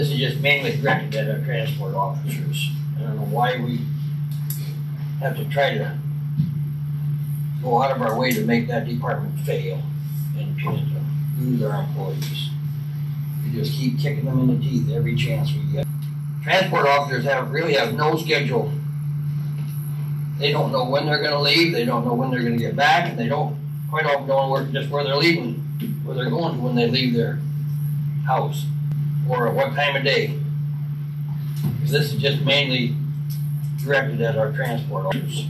Walworth County Commission meeting December 15th